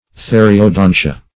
Theriodontia \The`ri*o*don"ti*a\, n. pl. [NL., fr. Gr. ? (dim.